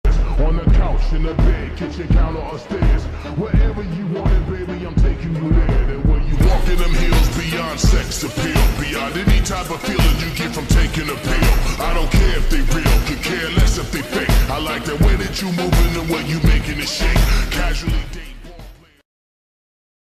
[Sorry For The Quality] HAPPY Sound Effects Free Download